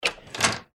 door02.mp3